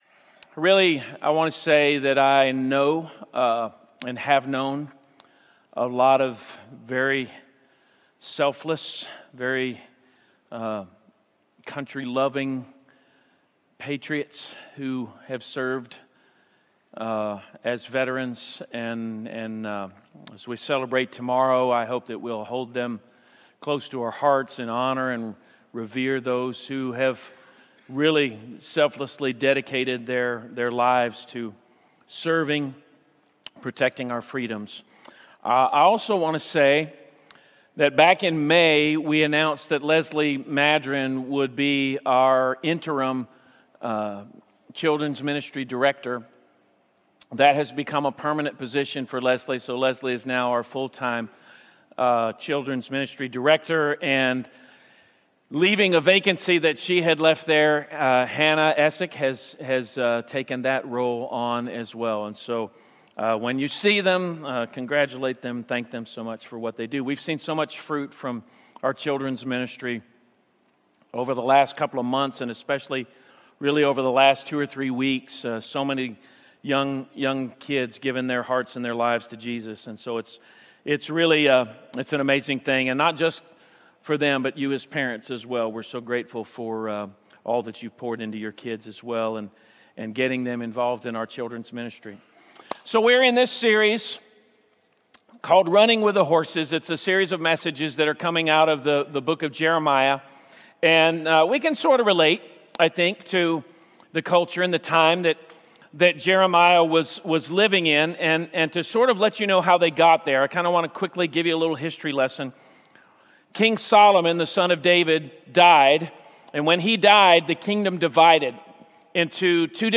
Sermons | Jefferson Christian Church